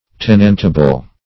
Search Result for " tenantable" : The Collaborative International Dictionary of English v.0.48: Tenantable \Ten"ant*a*ble\, a. Fit to be rented; in a condition suitable for a tenant.